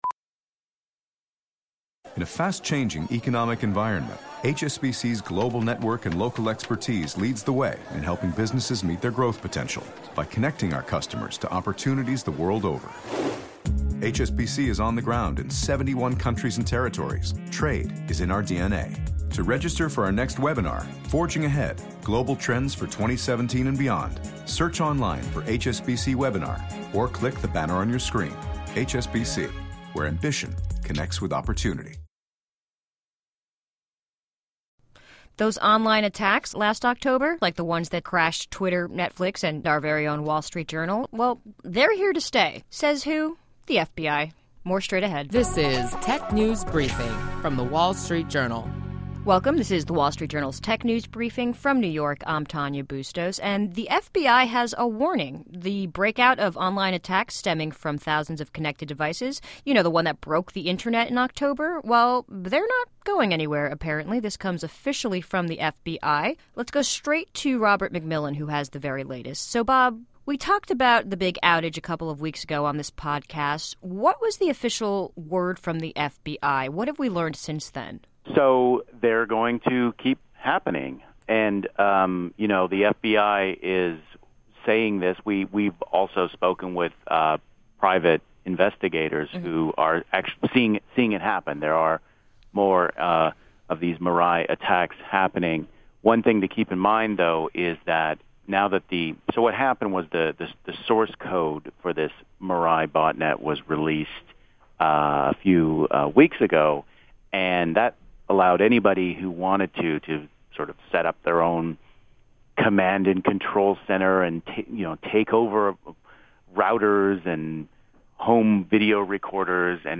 Listen to our WSJD reporters discuss notable tech company news, new tech gadgets, personal technology updates, app features, start-up highlights and more.